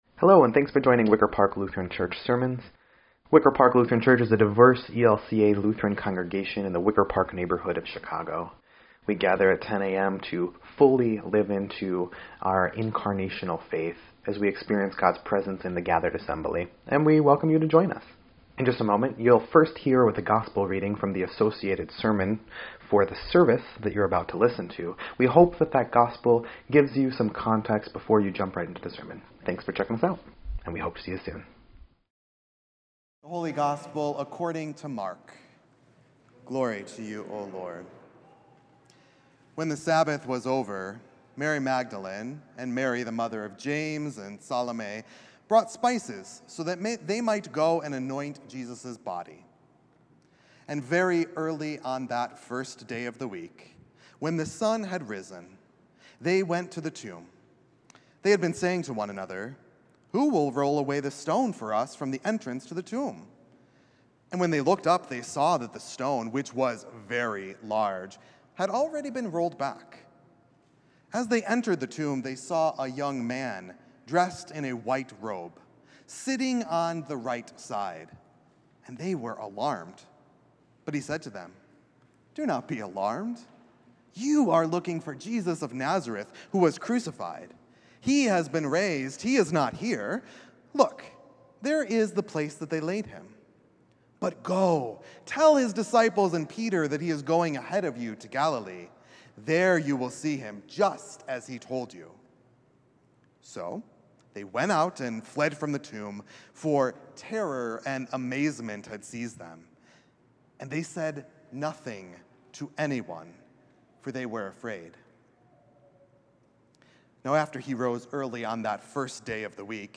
7.21.24-Sermon_EDIT.mp3